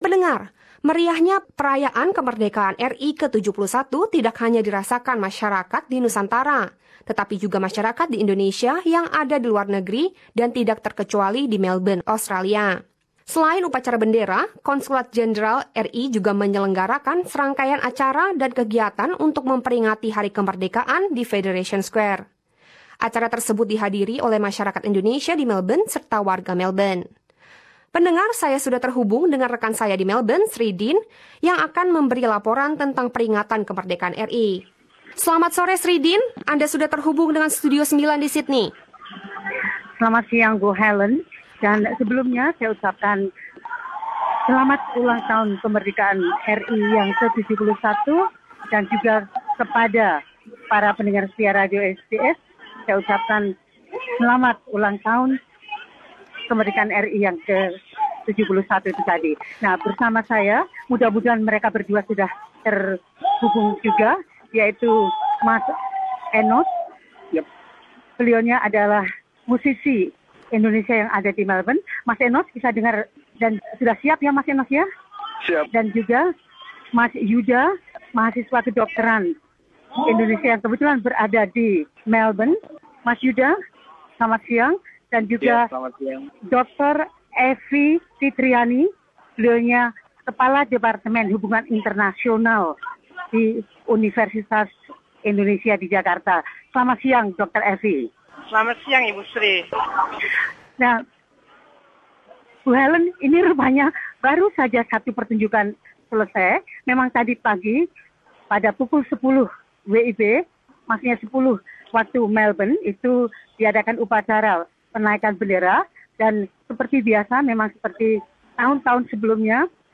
berada di Fed Square Melbourne dan berbibcang dengan beberapa masyarakat Indoneisa yang menrayakan hari kemerdekaan itu.